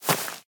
1.21.5 / assets / minecraft / sounds / block / vine / climb5.ogg
climb5.ogg